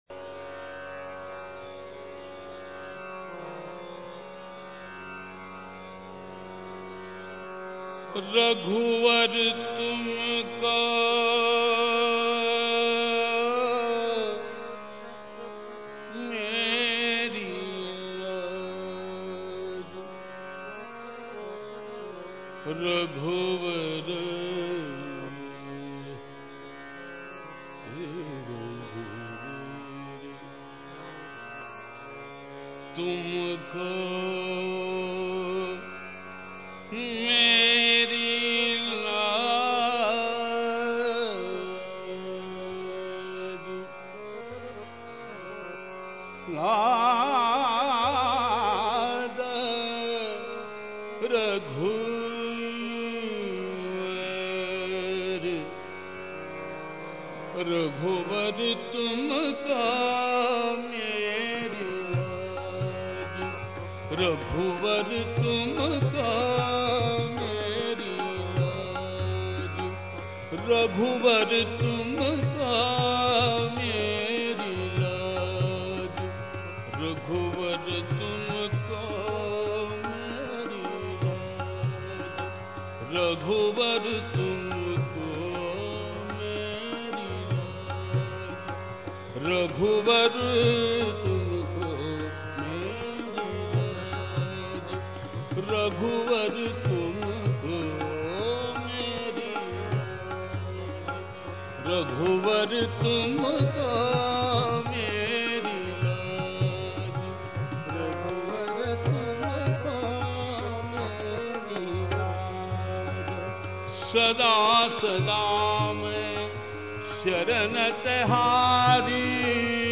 他的声音宛如喜玛拉雅飞鹰，低沉练达又随兴，从感性的唱音中流露出动人的情感，紧紧扣着印度声乐曲的灵魂。